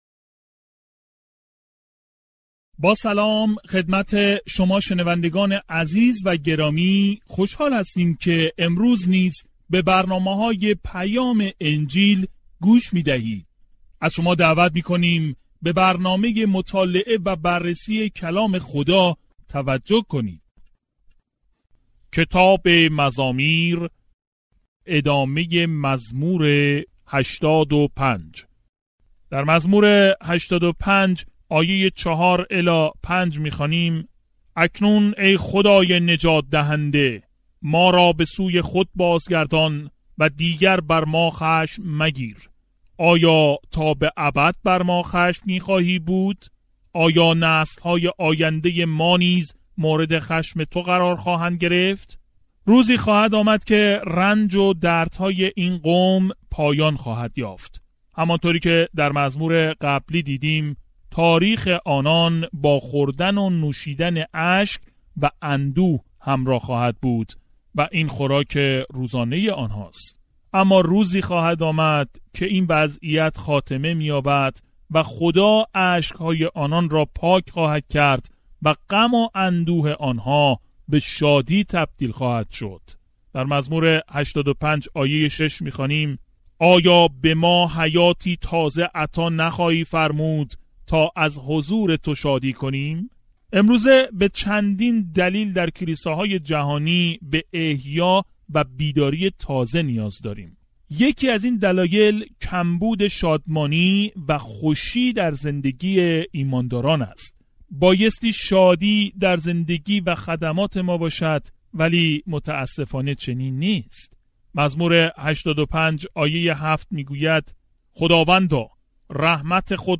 در حین گوش دادن به مطالعه صوتی و خواندن آیات انتخابی از کلام خدا، روزانه در مزامیر سفر کنید.